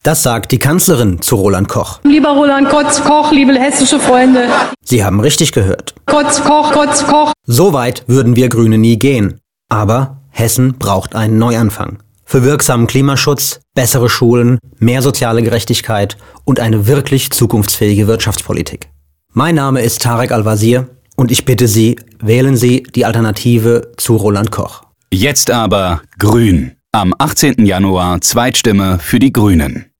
heute haben die hessischen GRÜNEN ihren Radiospot zum hessischen Landtagswahlkampf vorgestellt. Darin findet ein realer Versprecher von Bundeskanzlerin Angela Merkel auf dem letzten Bundesparteitag Verwendung, als sie sich Roland Koch wandte. Der Spitzenkandidat und Landesvorsitzende der GRÜNEN, Tarek Al-Wazir, hat den Radiospot selbst gesprochen.